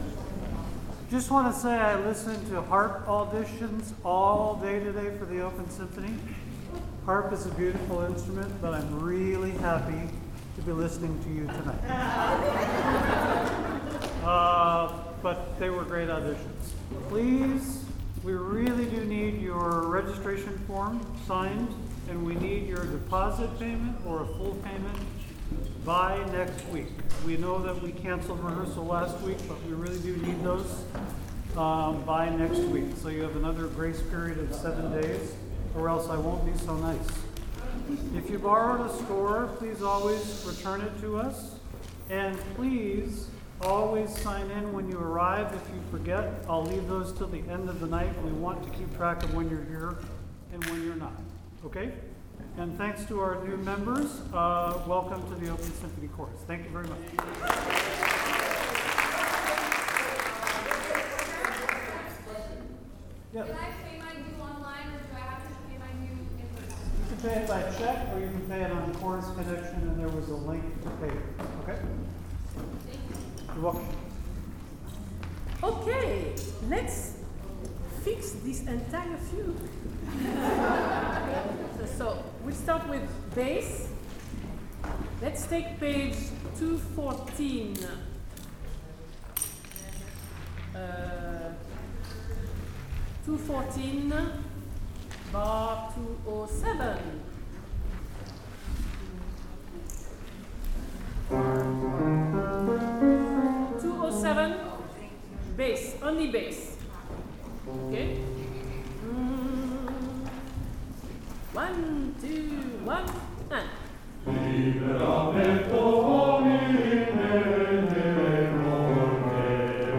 OSC Rehearsal - September 3, 2025
The podcast was recorded in two parts. Due to an unforeseen recording error, Part 2 is slightly cut short, so please read Part 2 carefully for what was covered after the recording stopped.